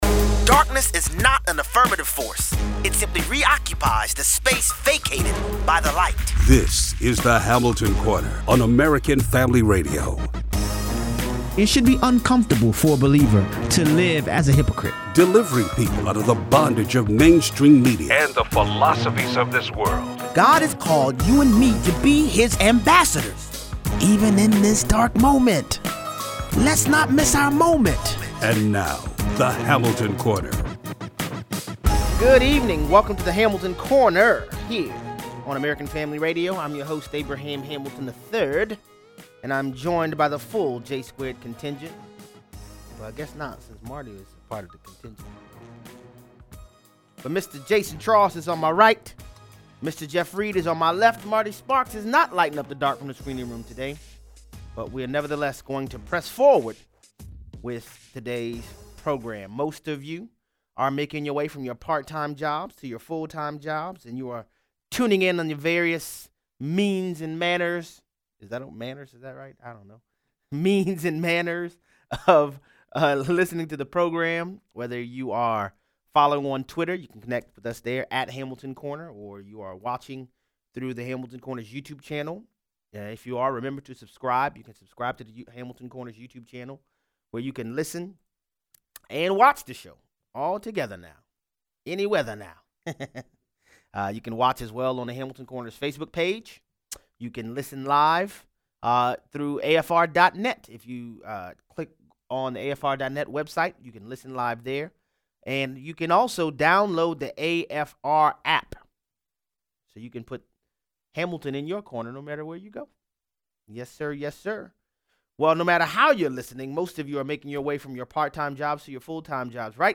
Joe Biden is denied communion in South Carolina. Callers weigh in.